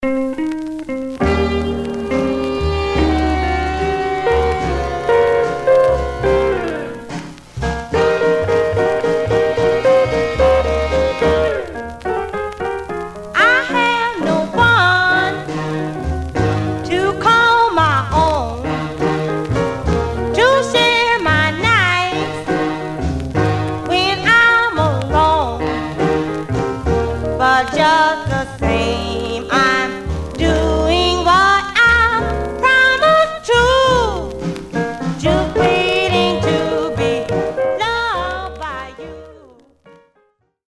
Condition Surface noise/wear Stereo/mono Mono
Rythm and Blues